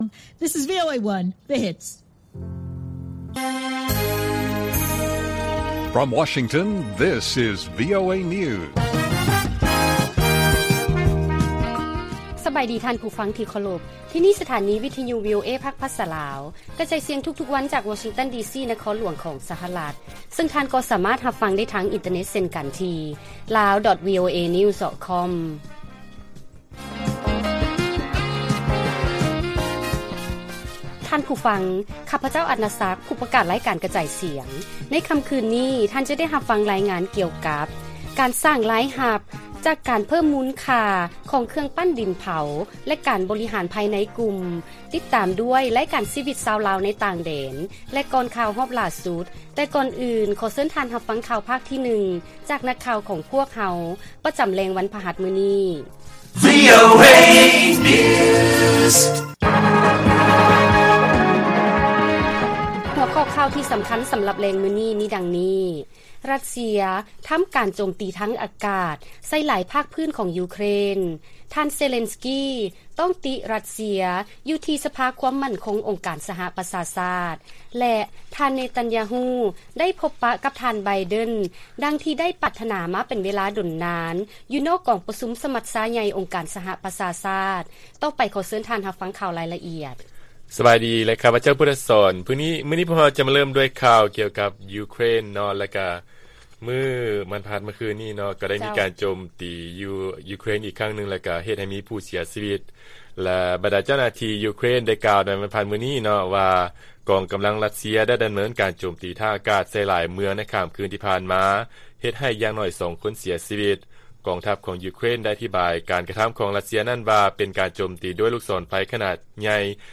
ລາຍການກະຈາຍສຽງຂອງວີໂອເອ ລາວ: ຣັດເຊຍ ທຳການໂຈມຕີທາງອາກາດໃສ່ຫຼາຍພາກພື້ນຂອງ ຢູເຄຣນ